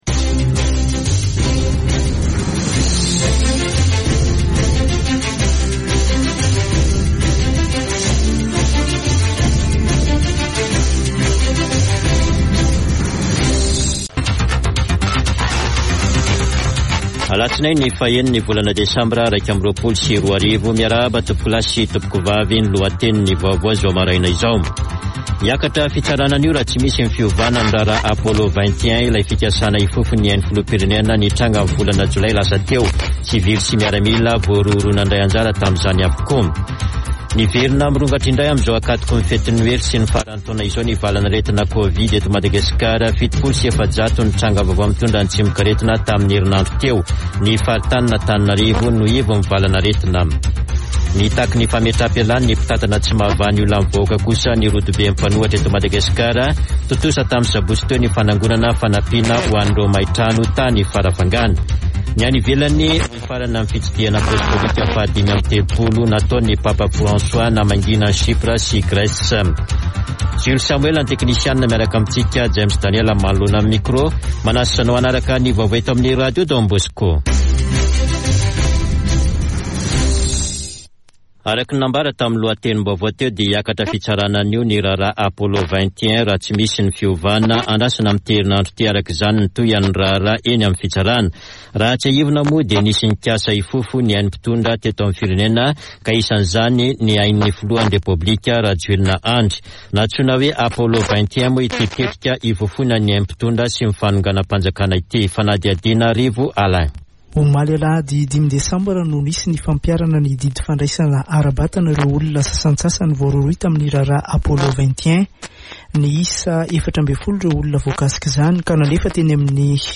[Vaovao maraina] Alatsinainy 06 desambra 2021